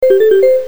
answer_ring.mp3